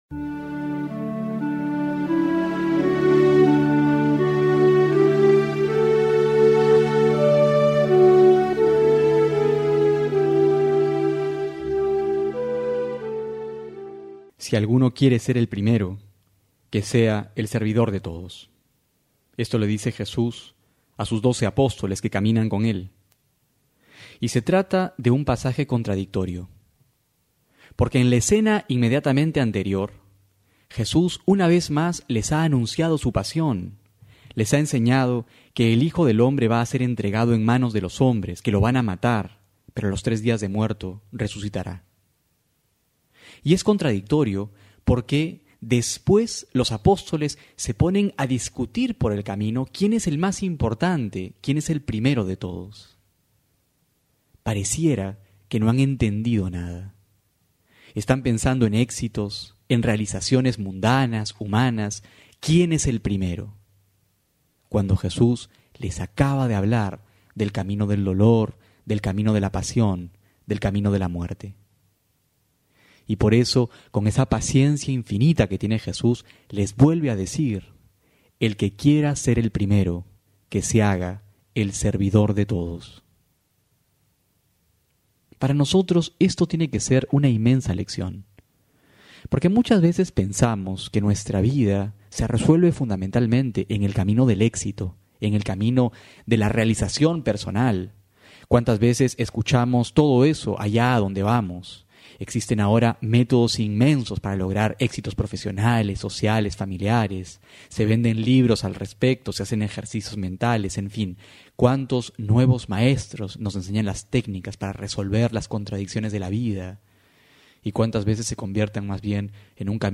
Homilía para hoy: Marcos 9,30-37
febrero21-12homilia.mp3